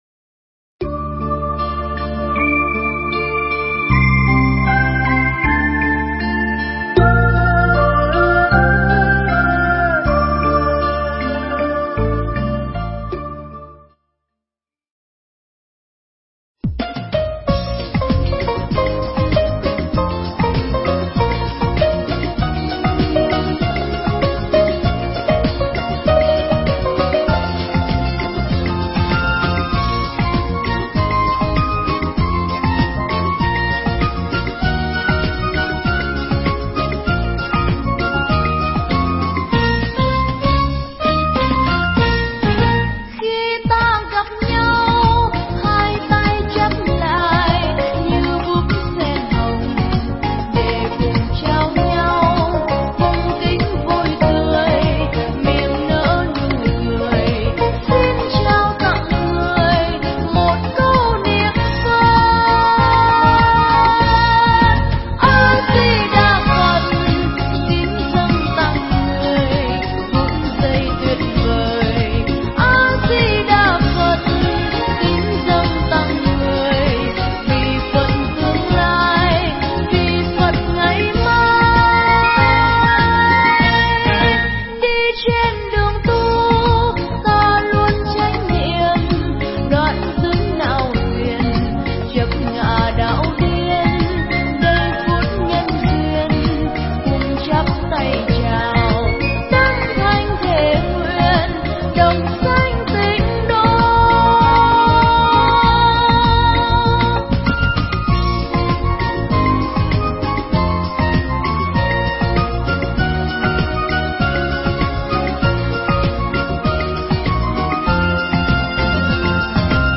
Nghe Mp3 thuyết pháp Làm Thế Nào Để Thanh Thản Khi Ngày 30 Tới